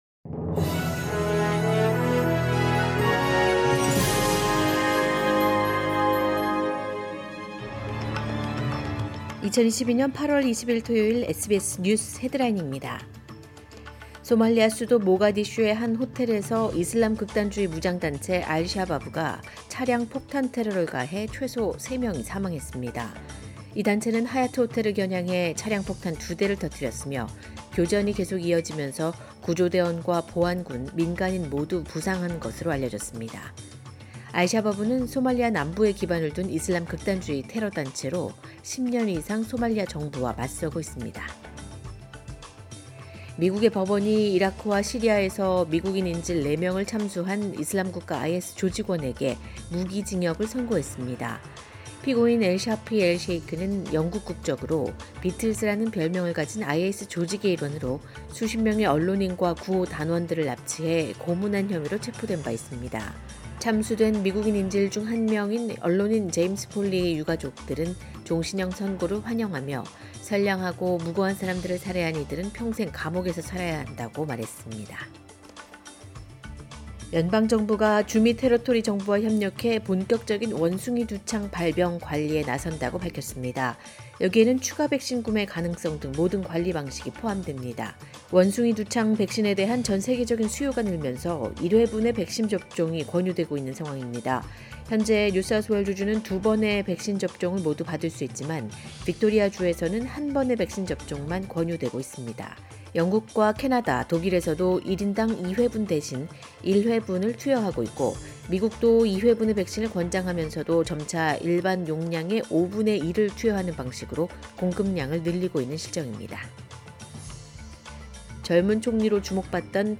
2022년 8월 20일 토요일 SBS 한국어 간추린 주요 뉴스입니다.